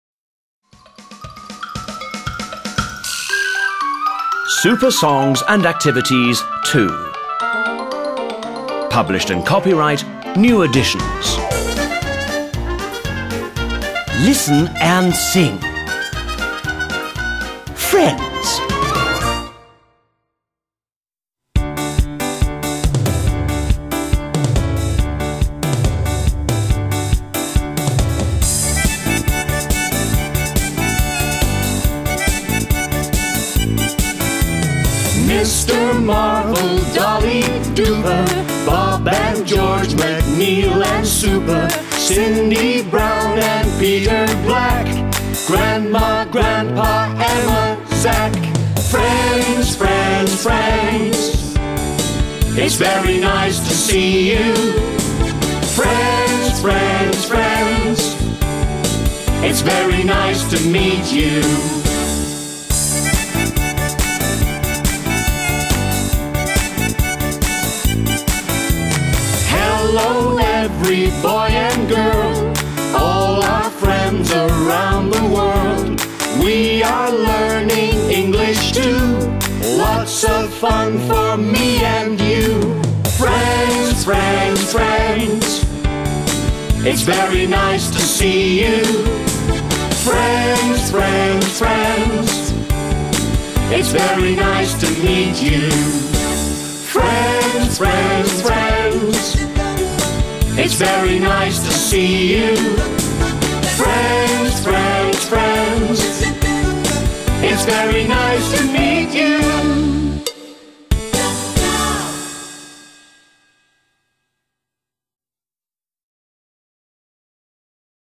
Фестиваль "День английского языка"
Песня "Friends" [1] (поют дети из 4-6 классов)